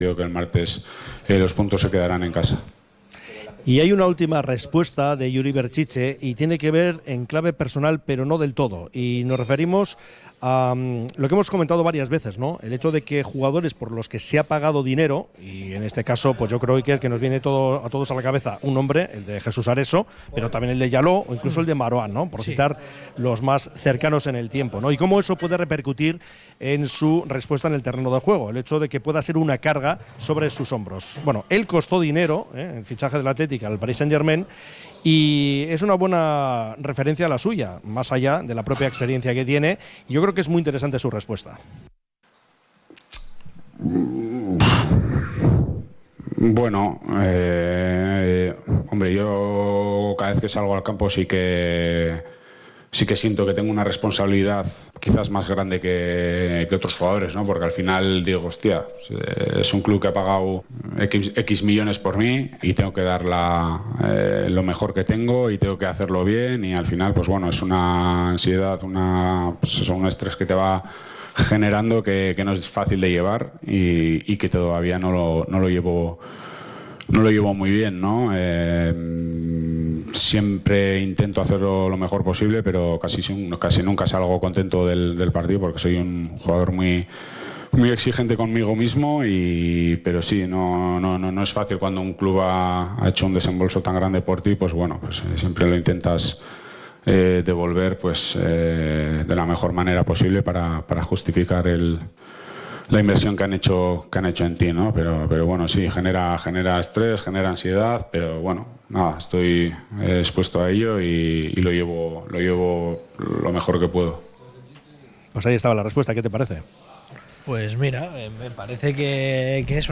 Como cada viernes desde el Bilbao Urban Sagardotegia